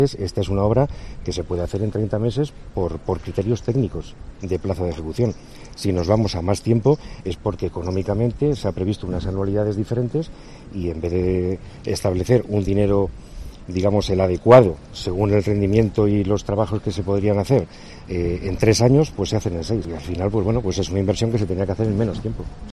Director general de Carreteras, Miguel Ángel Arminio